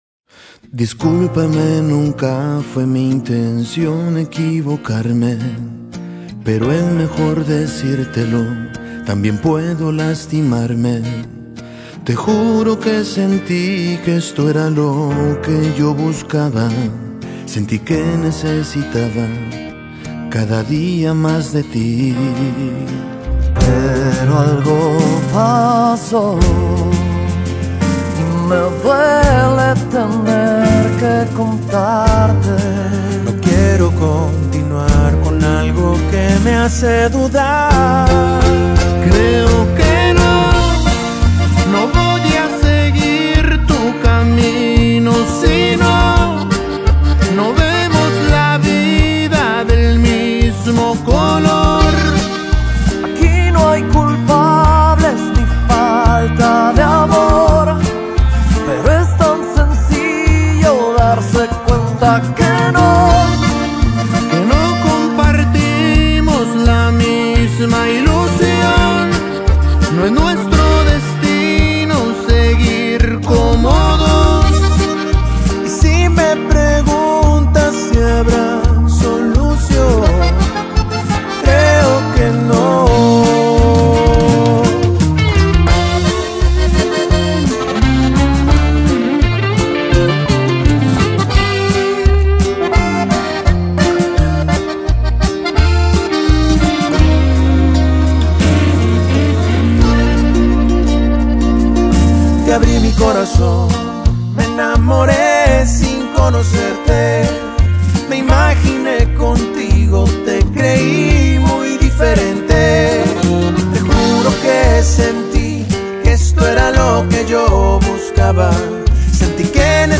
mezcla pop norteño bastante agradable y pegajosa al oído
cuenta con un perfecto balance de dos estilos musicales